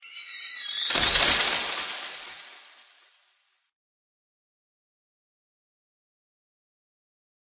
Scary Door Creak with Reverb
Scary Halloween door creaking.
32kbps-Triond-Door-Squeak.mp3